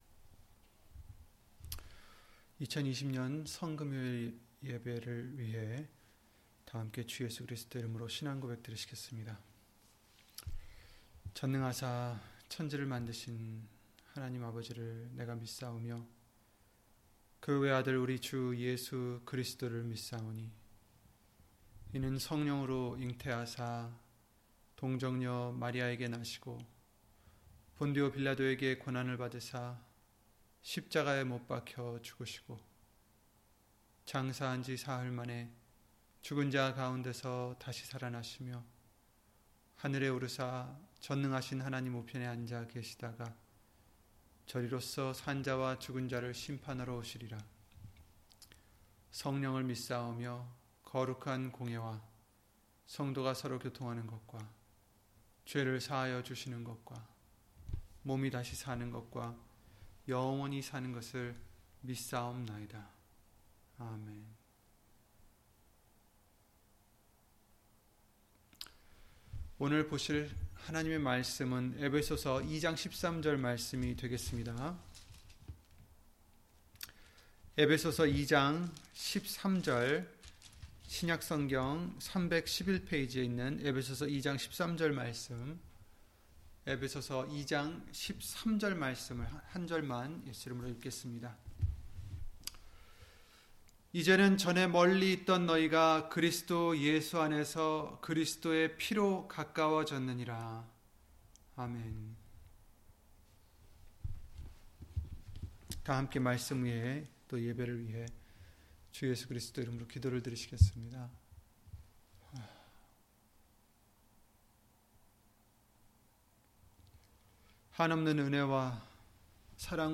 에베소서 2장 13절 [성 금요예배] - 주일/수요예배 설교 - 주 예수 그리스도 이름 예배당